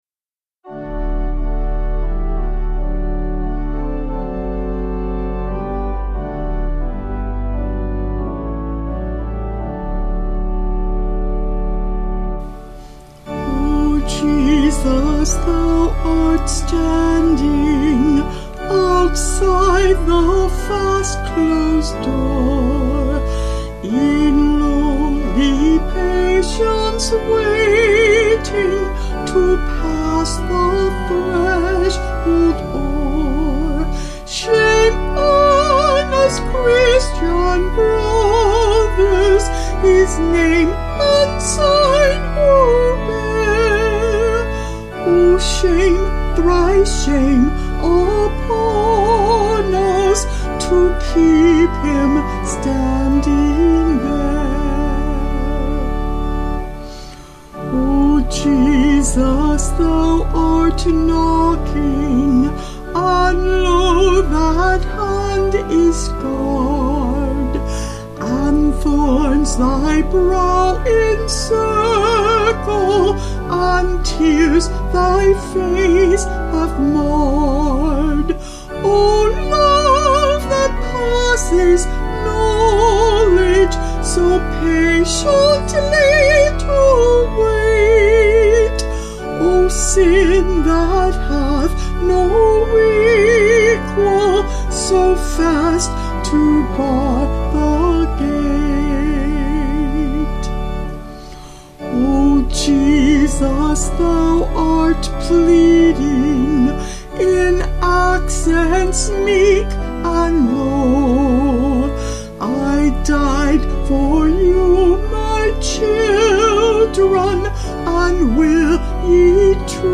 Vocals and Organ   262.1kb Sung Lyrics